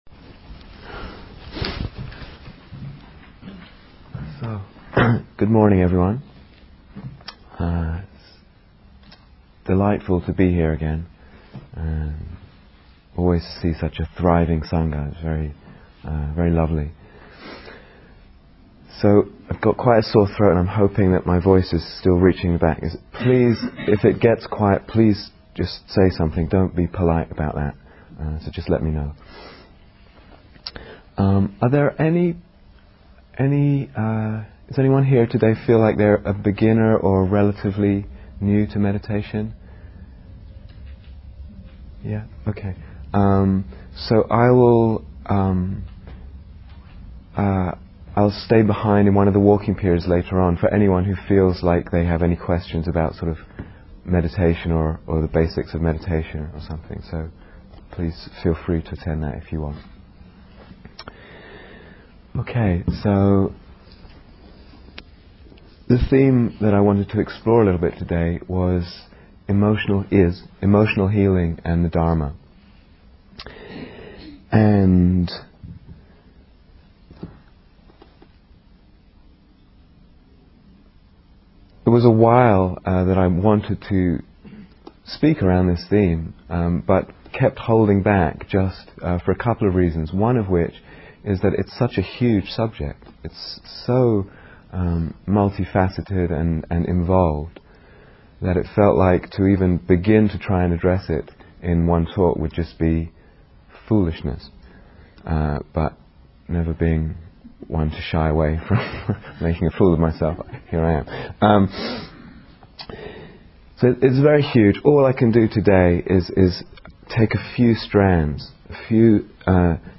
Emotional Healing (Part One) Download 0:00:00 --:-- Date 9th December 2007 Retreat/Series Day Retreat, London Insight 2007 Transcription Good morning, everyone.